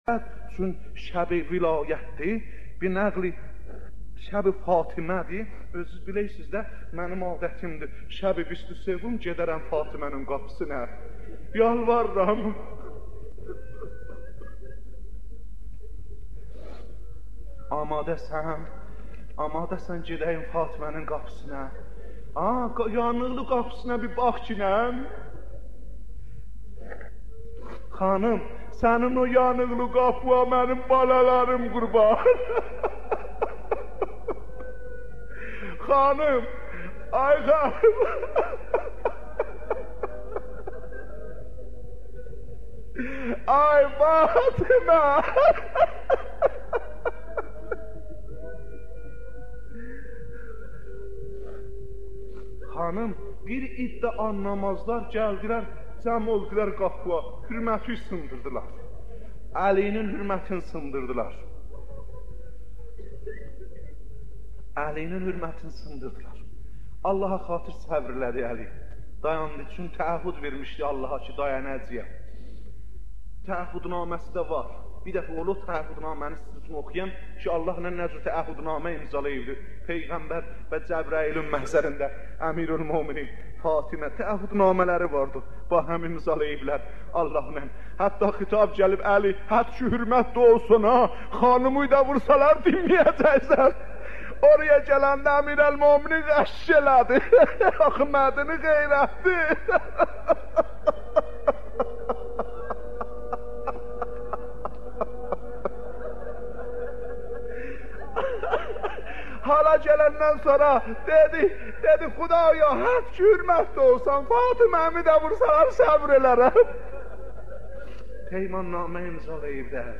مداحی و نوحه
دانلود مداحی روضه ضربت خوردن حضرت